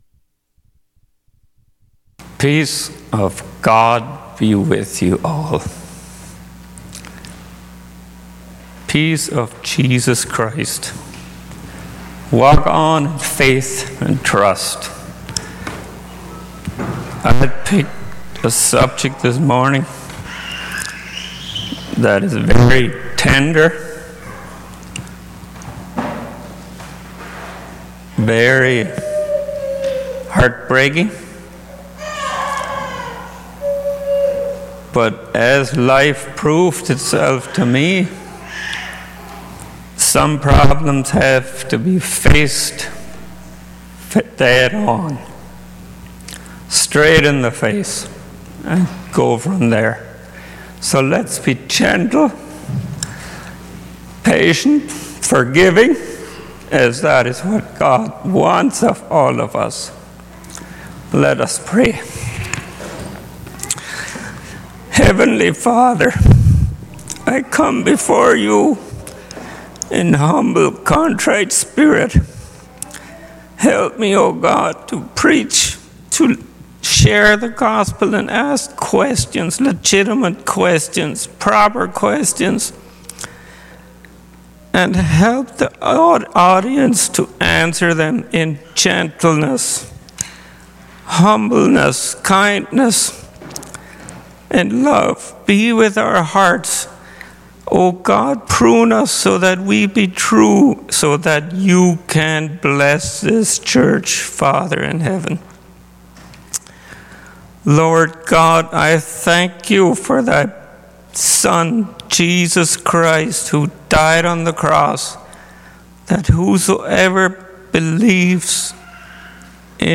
Sunday Morning Bible Study Passage: 2 Timothy 2:1-26 Service Type